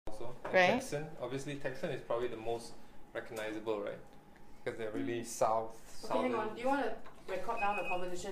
TEMP: Singlish 9.1
UESinglish-9.1.mp3